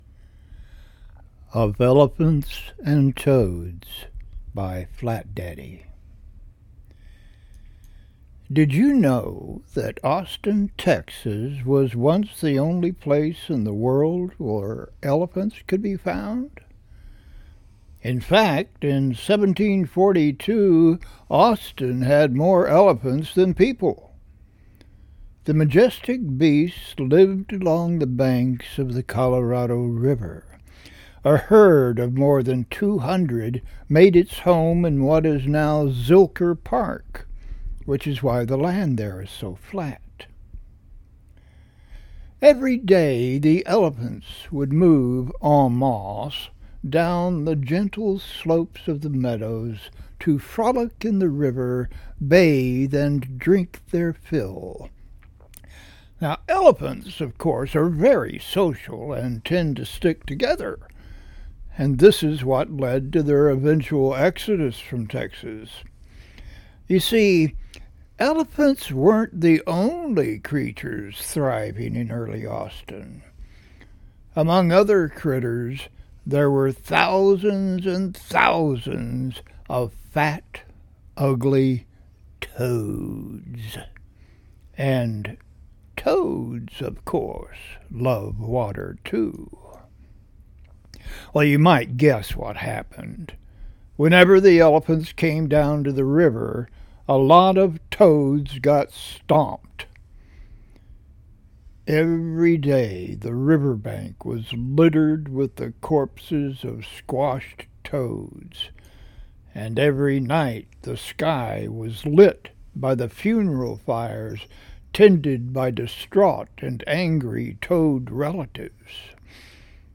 A long time ago in what is now Austin, Texas, two very different species went to war against each other -- with hilarious results! This a short story/performance piece I performed many times for children and adults alike to smiles and laughter.